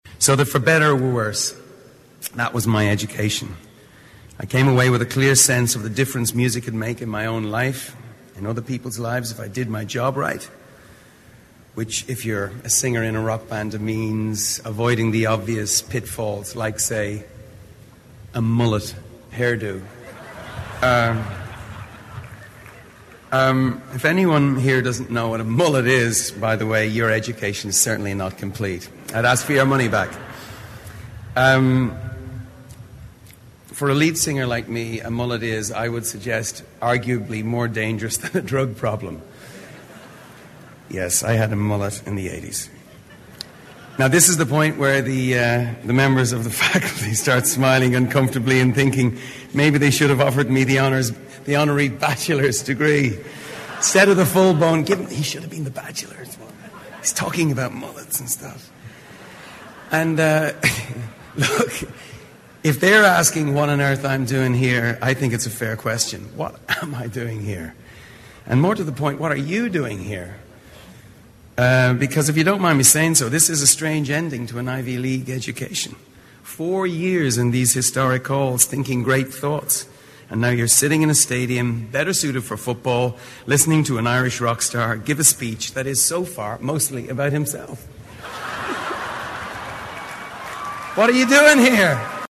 名人励志英语演讲 第157期:奏出生命中最美的乐曲(4) 听力文件下载—在线英语听力室